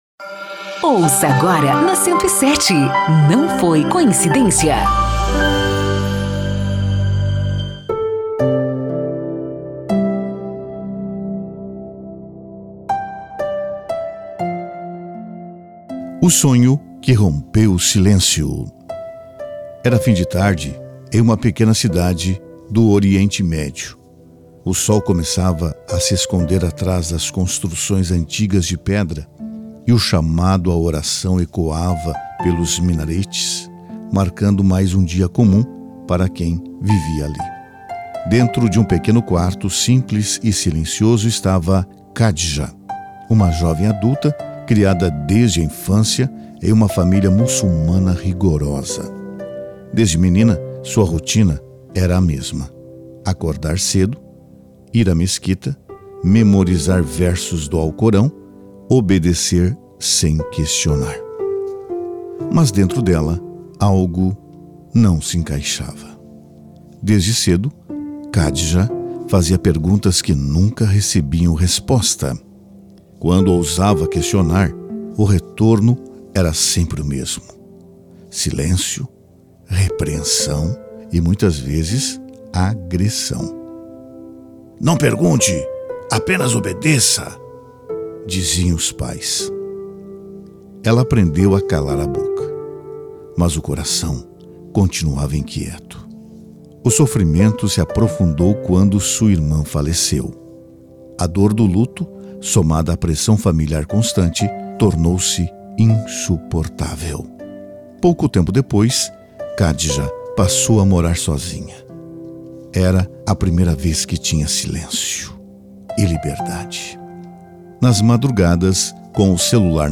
Testemunhos